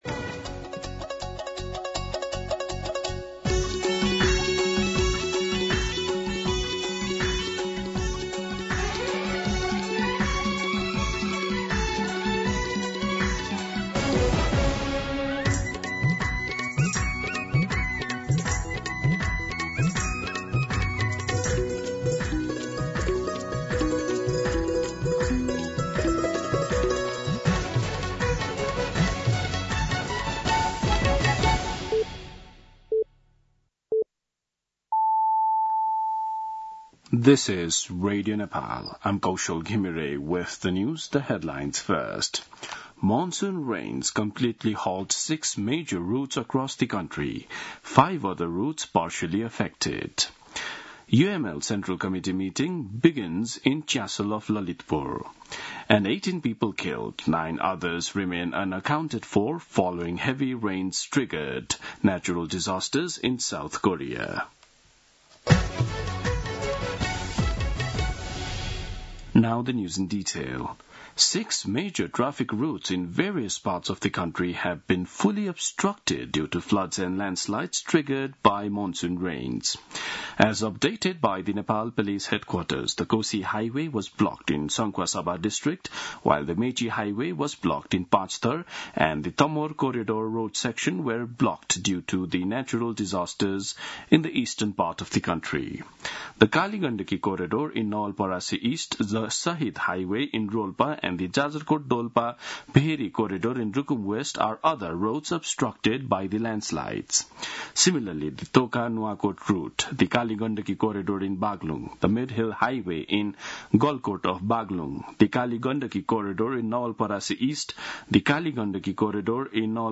दिउँसो २ बजेको अङ्ग्रेजी समाचार : ५ साउन , २०८२